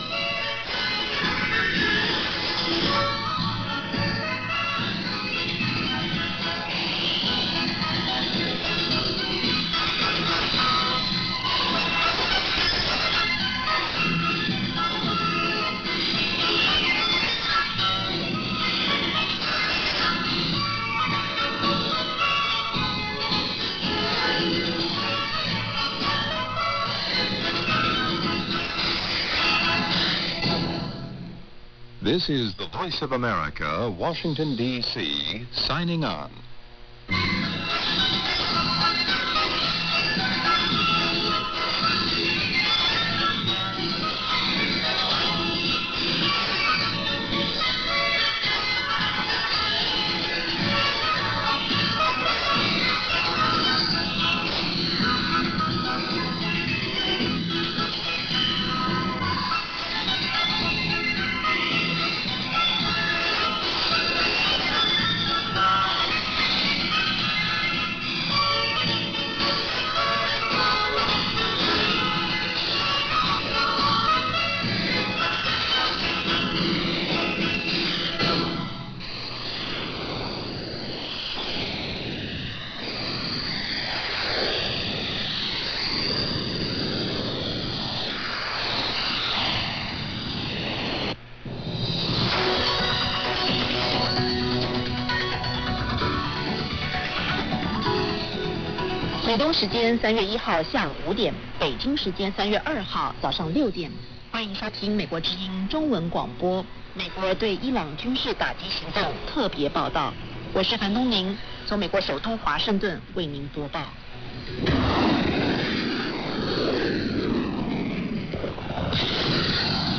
It was the familiar tune of Yankee Doodle being played by the Voice of America on 7500 kHz just prior to 2200 hours UTC.
Though none of the actual programs are in English, the ID at the beginning and end of the transmission are.
They were made using Kiwi SDR’s located in Europe.